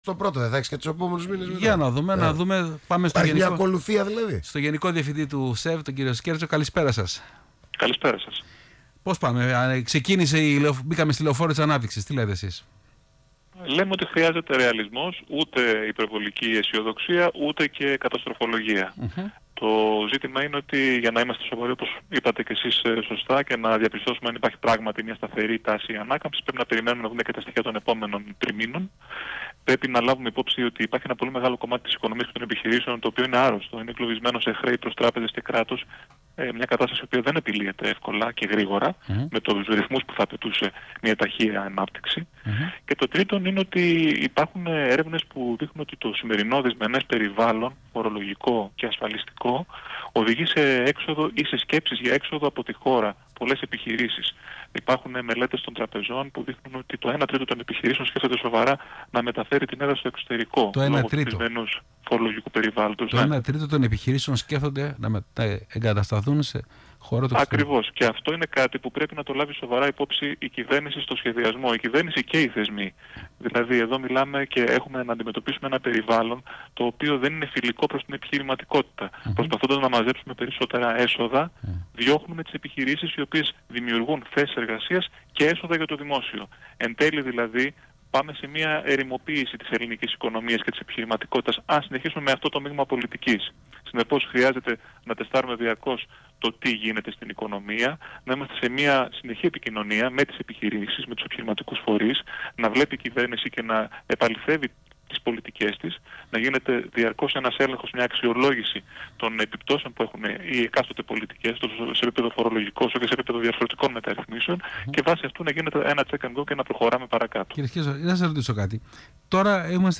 Ο Γενικός Διευθυντής του ΣΕΒ, κ. Άκης Σκέρτσος στον Ρ/Σ ACTION FM, 15/11/2016